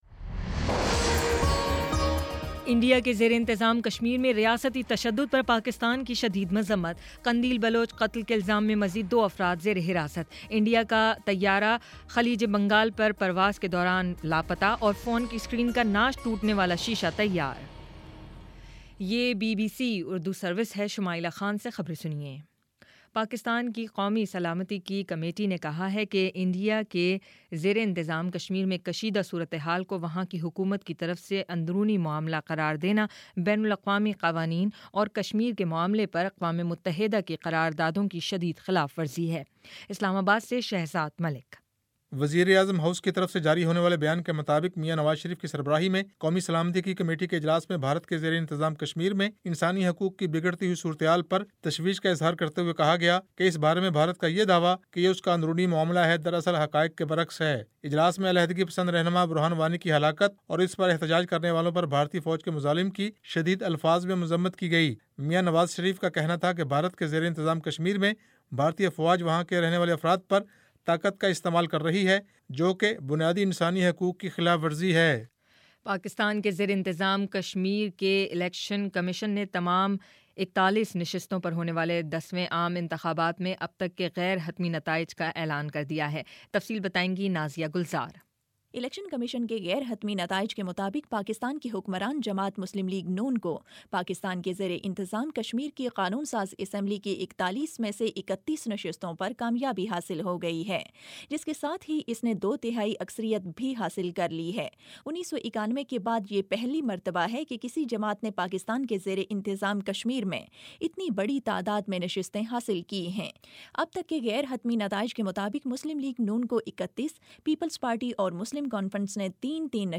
جولائی 22 : شام پانچ بجے کا نیوز بُلیٹن